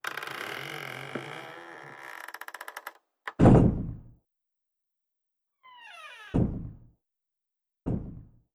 SFX_Toilet_Idle.wav